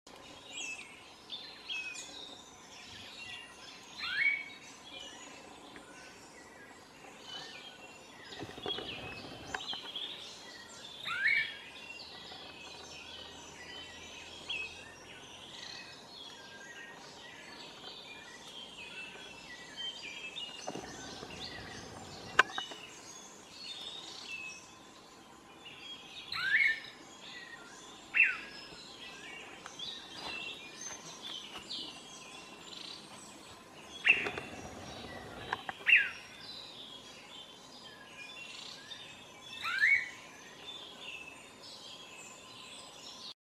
Yungas Manakin (Chiroxiphia boliviana)
Life Stage: Adult
Location or protected area: Parque Nacional Calilegua
Condition: Wild
Certainty: Photographed, Recorded vocal